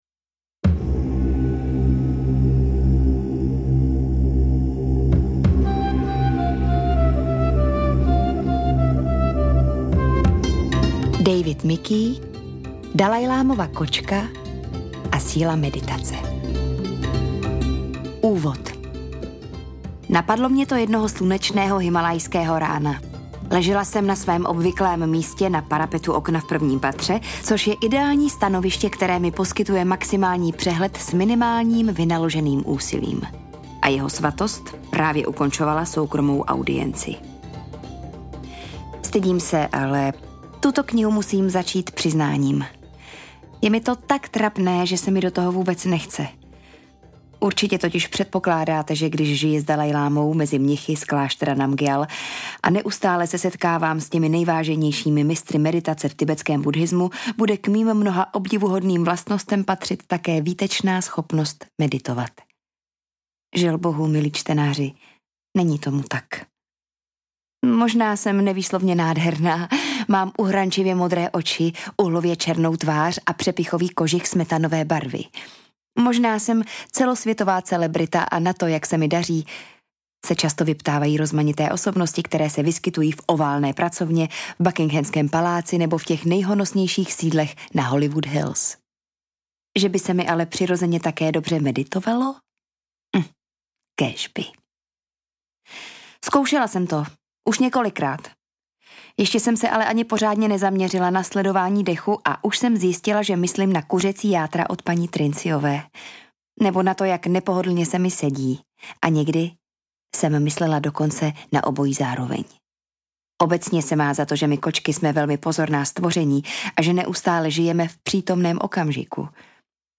Dalajlamova kočka a síla meditace audiokniha
Ukázka z knihy
• InterpretIvana Jirešová